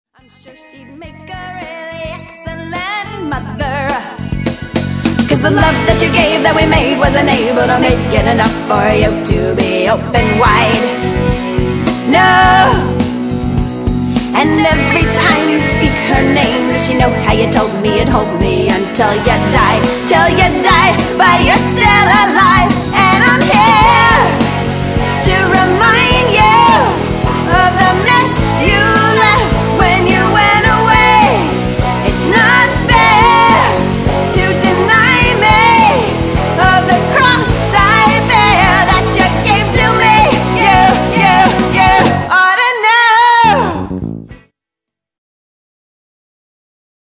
VOCAL DEMO
pop-rock